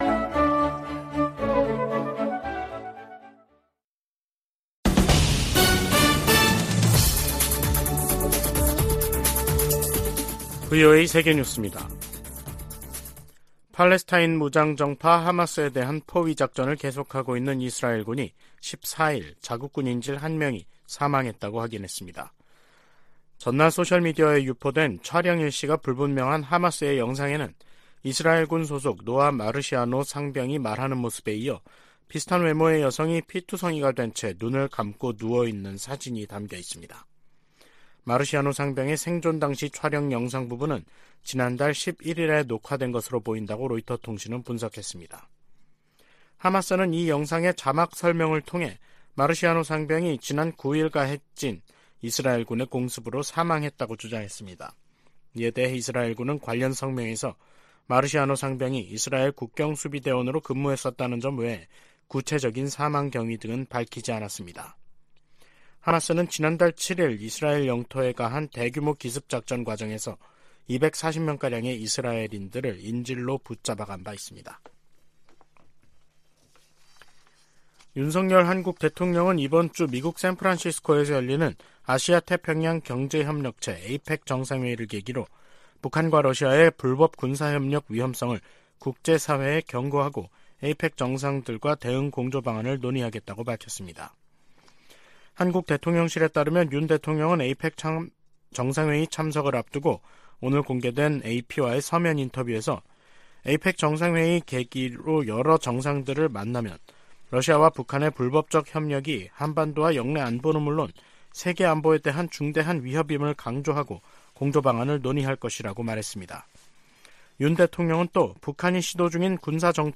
VOA 한국어 간판 뉴스 프로그램 '뉴스 투데이', 2023년 11월 14일 3부 방송입니다. 한국을 방문한 로이드 오스틴 미 국방장관은 한반도 평화와 안정에 대한 유엔군사령부의 약속은 여전히 중요하다고 강조했습니다. 미 국무부는 이번 주 열리는 아시아태평양 경제협력체(APEC) 회의를 통해 내년도 역내 협력을 위한 전략적 비전이 수립될 것이라고 밝혔습니다. 15일 미중정상회담에서 양자 현안뿐 아니라 다양한 국제 문제들이 논의될 것이라고 백악관이 밝혔습니다.